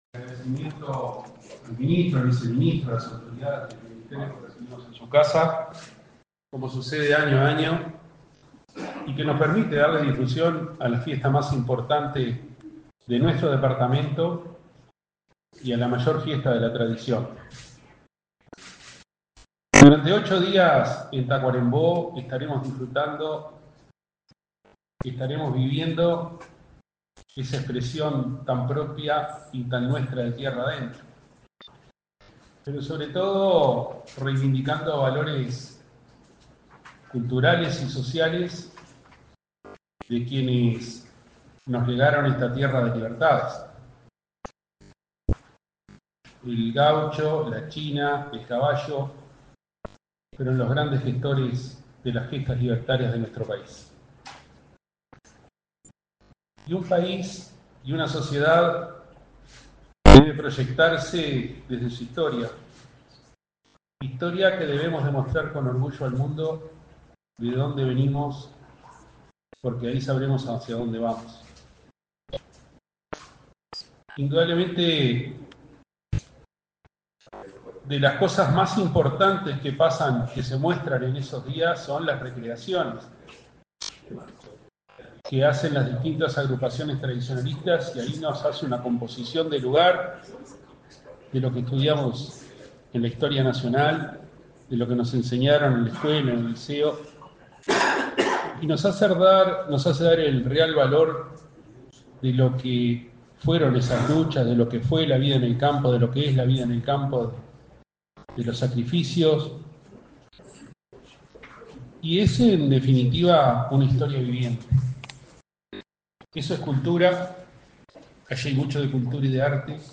Acto de presentación de la Fiesta de la Patria Gaucha
Acto de presentación de la Fiesta de la Patria Gaucha 04/02/2025 Compartir Facebook X Copiar enlace WhatsApp LinkedIn El Ministerio de Turismo presentó, este 4 de febrero, la 38.ª Fiesta de la Patria Gaucha, que se desarrollará entre el 15 y el 23 de marzo en el predio de la laguna de las Lavanderas, en Tacuarembó. Participaron en el evento el intendente de Tacuarembó, Wilson Ezquerra, y el ministro Eduardo Sanguinetti.